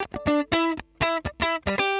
120_Guitar_funky_riff_E_7.wav